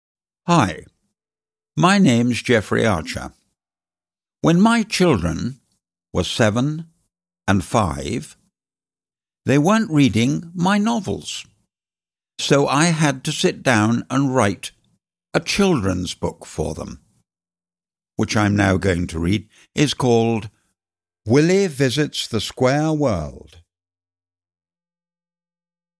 Willy Visits the Square World (EN) audiokniha
Ukázka z knihy
• InterpretJeffrey Archer